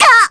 Viska-Vox_Damage_kr_02.wav